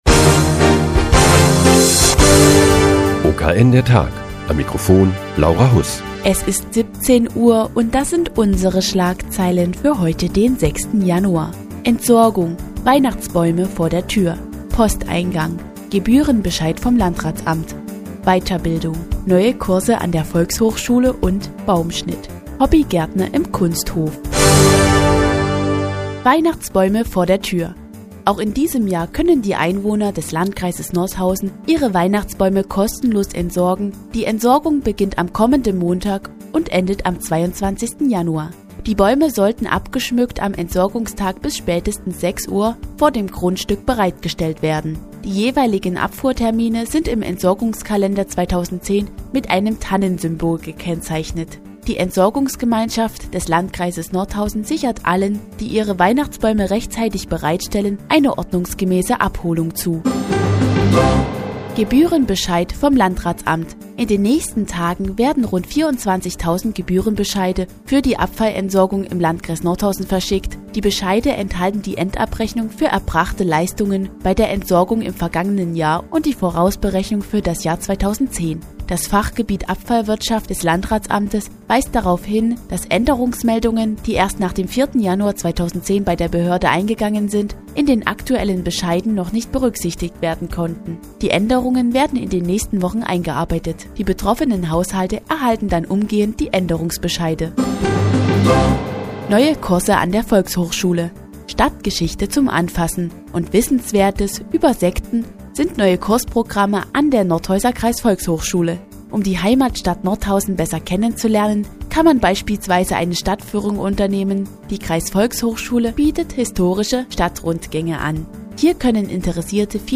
Die tägliche Nachrichtensendung des OKN ist nun auch in der nnz zu hören. Heute geht es um die Weihnachtsbaumentsorgung und neue Kurse an der Kreisvolkshochschule.